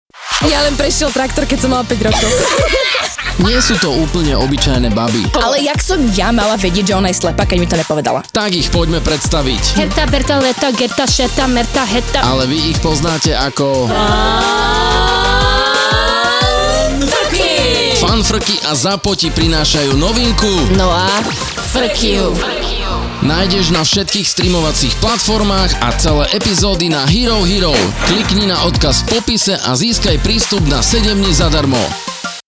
„Podcast s uletenými babami, ktoré riešia ešte uletenejšie témy.
Očakávaj veľa primitívneho humoru a smiechu cez plač.